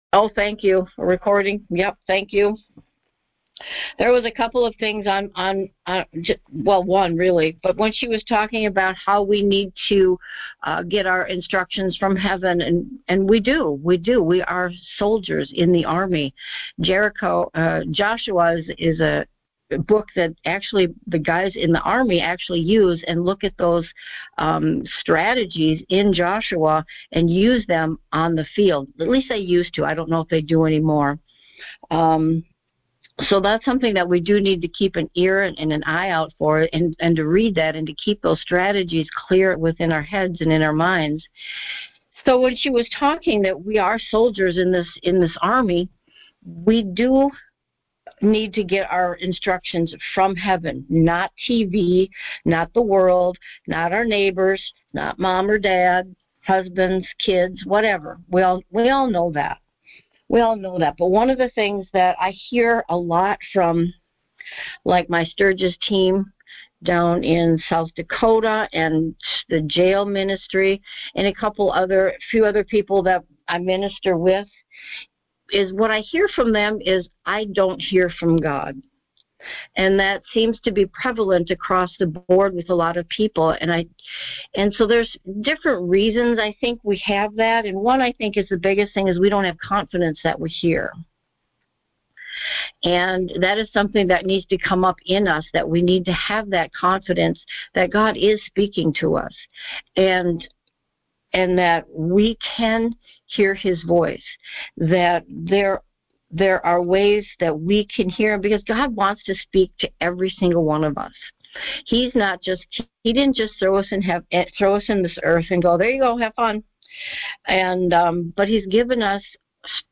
Sermons | Garden of Eden Ministries